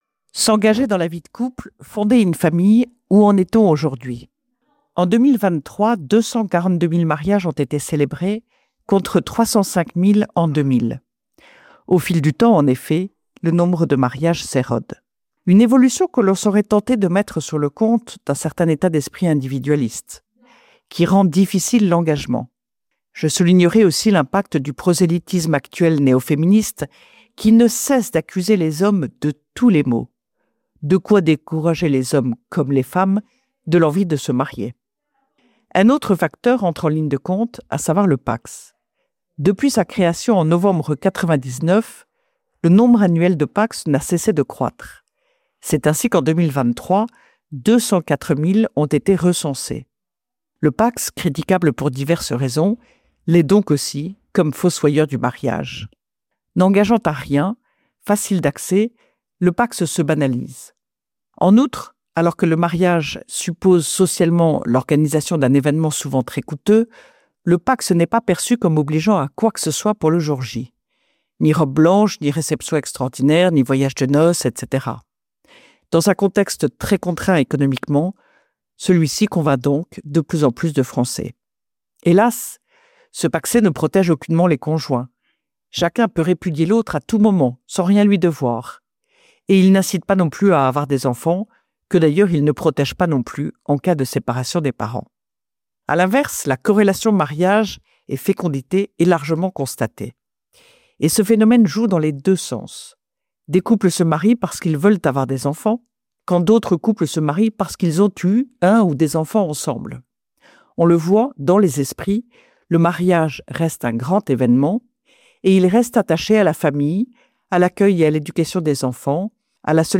Retrouvez chaque semaine la chronique
diffusée le samedi sur Radio Espérance